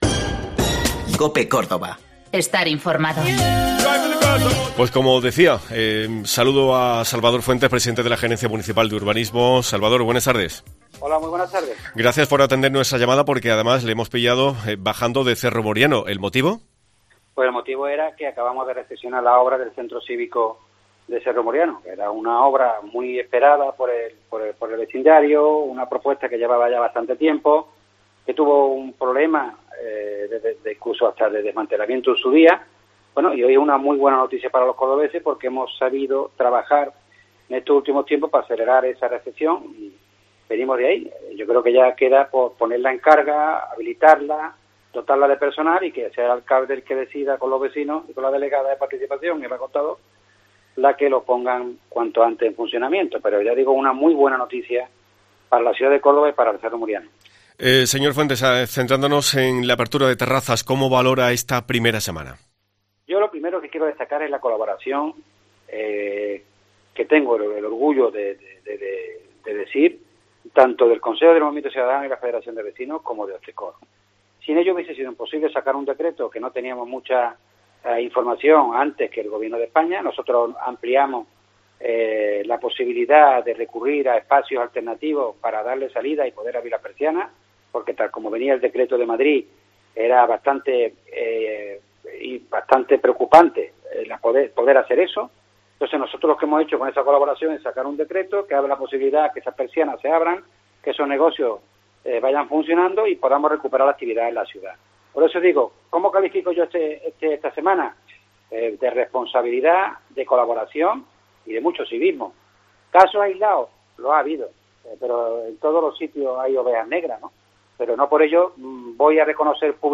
Por los micrófonos de COPE ha pasado el presidente de la Gerencia Municipal de Urbanismo, Salvador Fuentes, que ha señalado que la gerencia va a crear un protocolo rápido de autorización para la ampliación de veladores, de forma que sean capaces de dar respuesta a las peticiones realizadas por los hosteleros cordobeses.
Salvador Fuentes, presidente de la Gerencia Municipal de Urbanisno, responde las preguntas